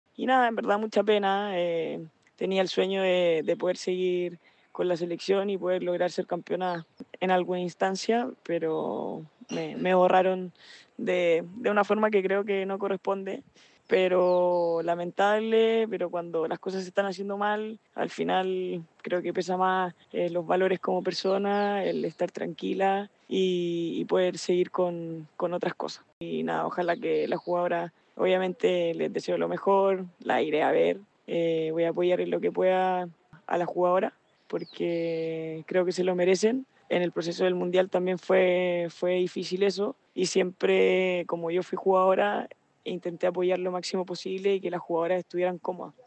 En conversación con ADN TOP, la excampeona mundial fustigó a la Federación de Patinaje por sacarla del cargo.